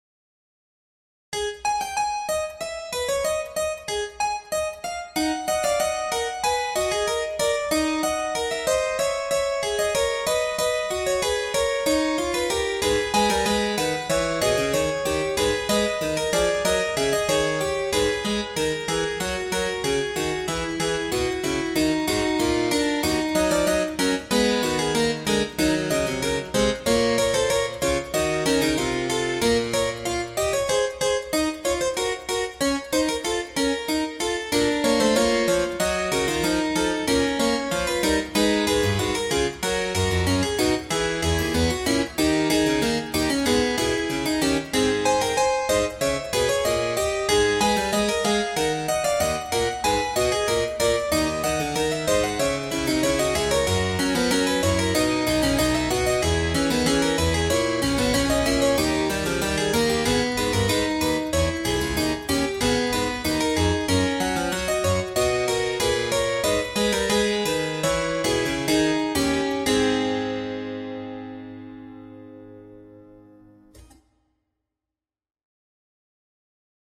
- Piano Music, Solo Keyboard - Young Composers Music Forum
I liked the opening of this but after a little bit I started to drift away from listening because the rhythm became static.
This is a very small and simple Fugue i made in one sitting, im still pretty bad at making fugues and baroque music in general, so please have a bit of mercy on me!